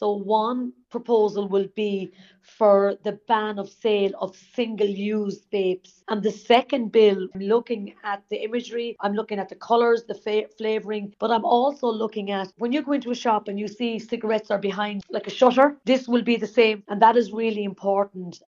The Junior Health Minister says there are two other pieces of legislation in the works: